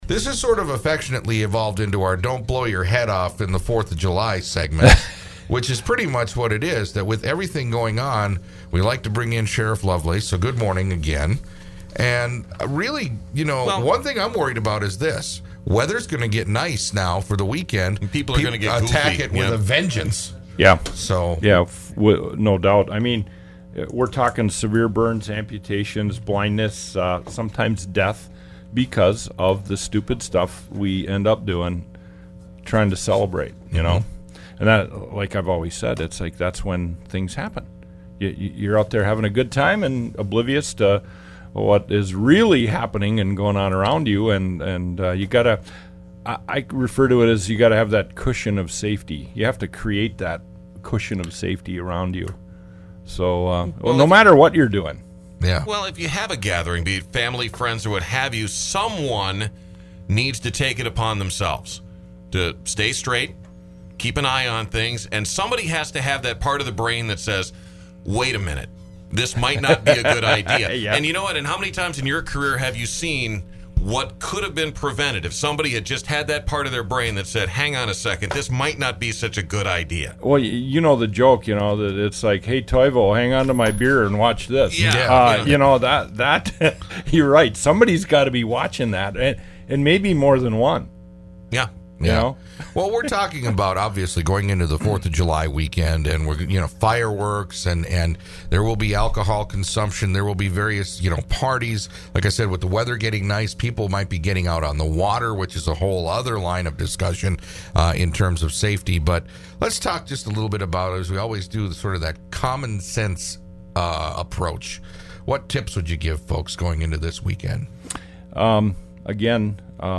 Old Interviews Archive
Sheriff Lovelace is always good for a bit of humor to be mixed in with his message.